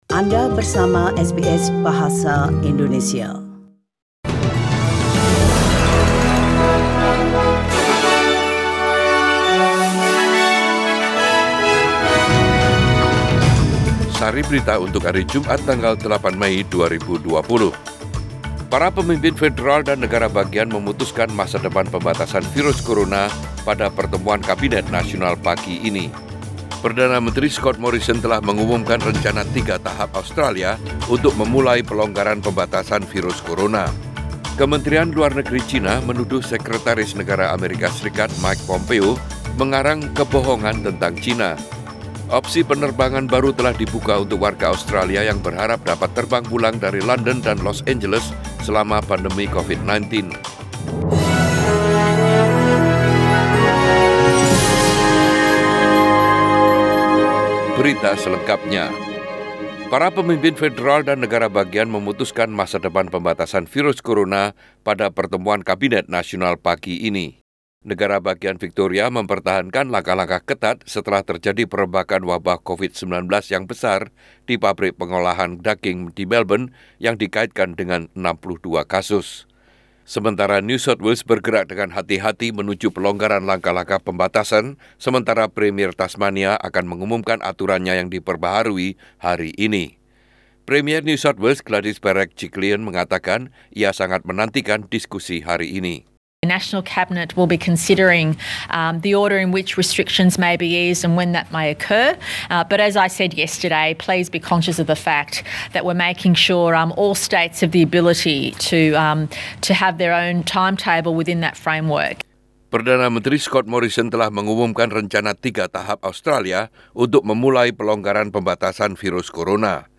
SBS Radio news in Bahasa Indonesia - 8 May 2020